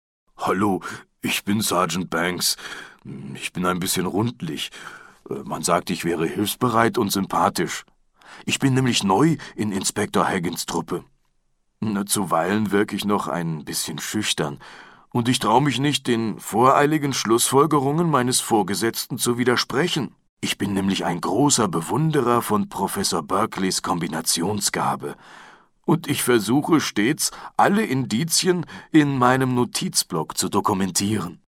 mehr über das Hörbuch